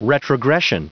Prononciation du mot retrogression en anglais (fichier audio)
Prononciation du mot : retrogression